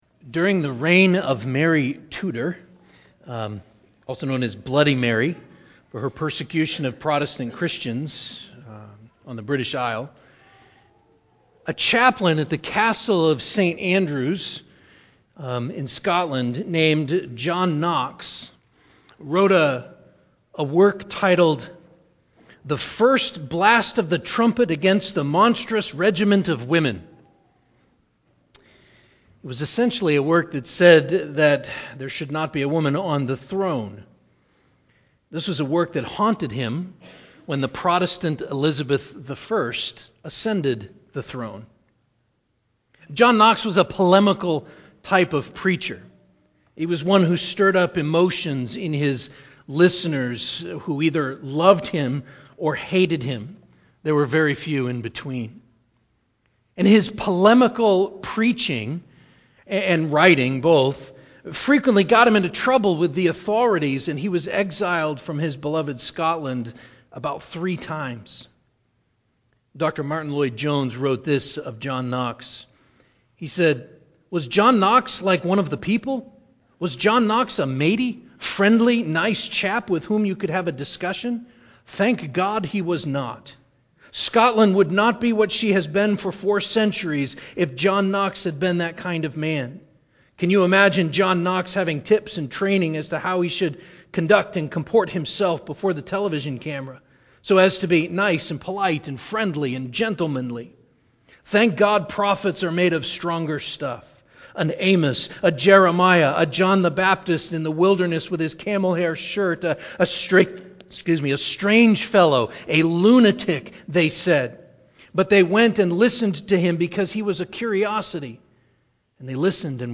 9.12.21-SERMON-AUDIO-CD.mp3